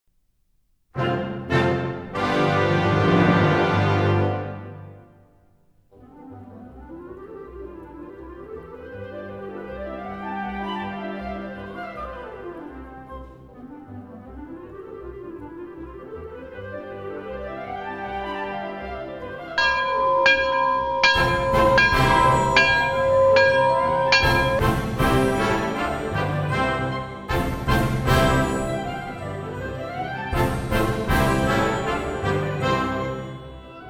Instrument: Orchestra
Period: Romantic